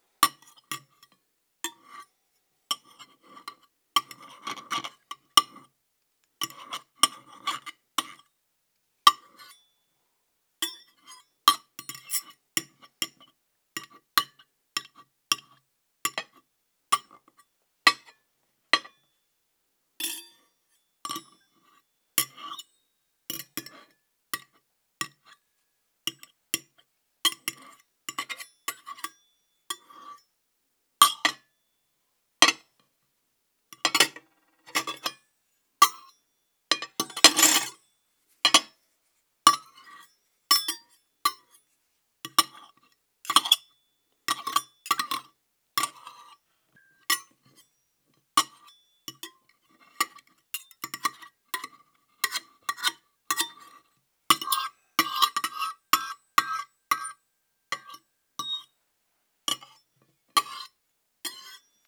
FOODTware-Couverts-manipulations-sur-assiette-ID-1193-LS.wav